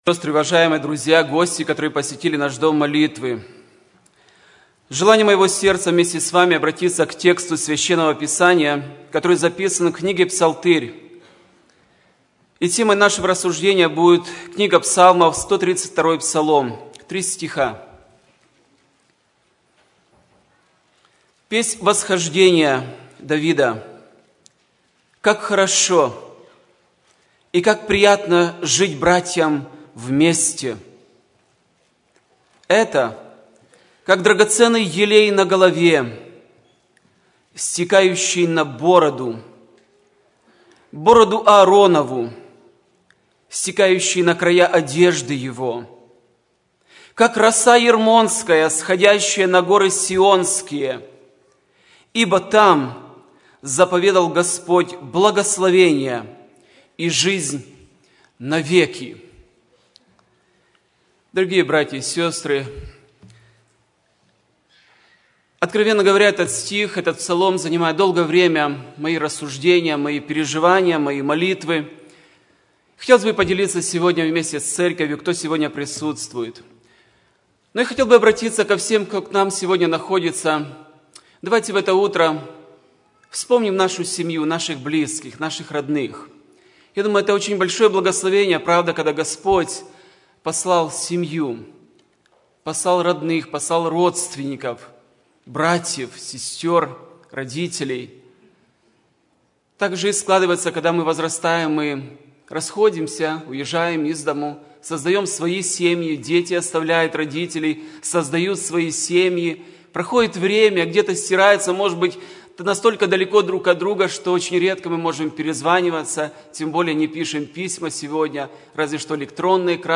Все Проповеди